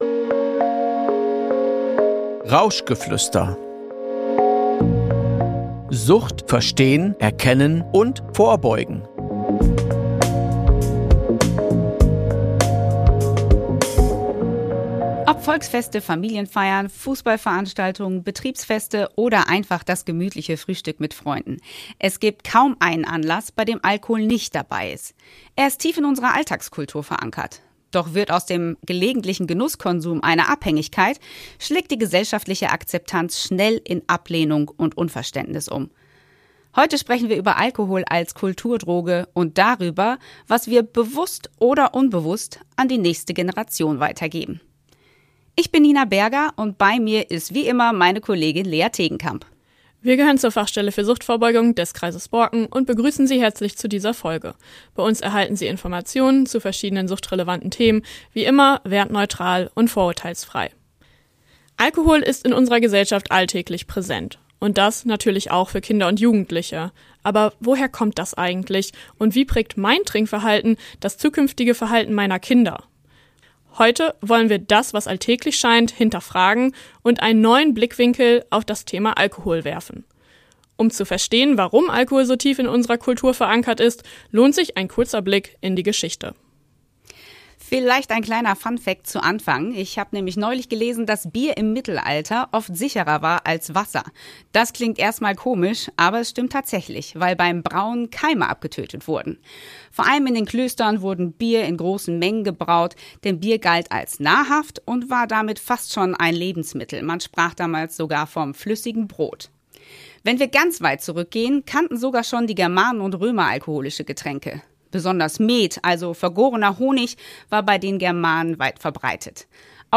Dabei erläutern sie, warum es sich lohnt, Denk- und Verhaltensweisen, die wir durch unsere Sozialisierung erlernt haben, kritisch zu hinterfragen. Ein Gespräch, das informiert, einordnet und stärkt – für alle, die mit jungen Menschen im Austausch bleiben wollen.